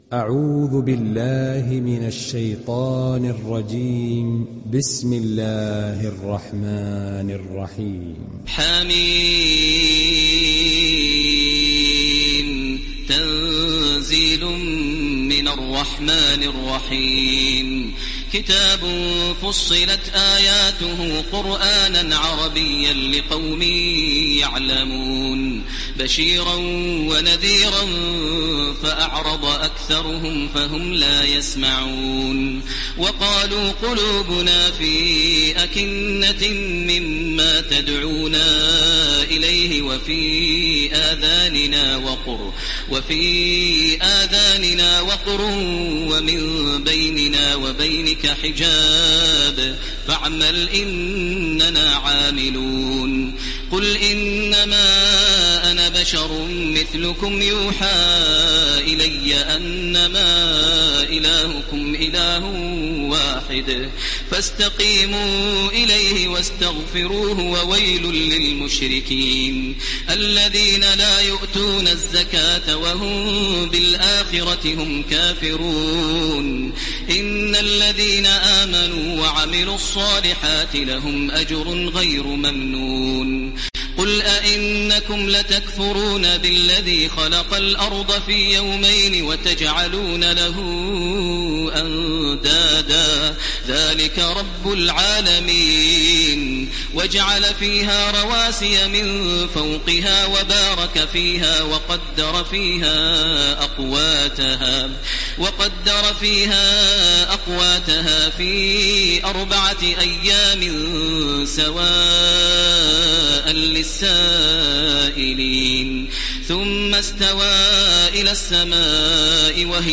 ডাউনলোড সূরা ফুসসিলাত Taraweeh Makkah 1430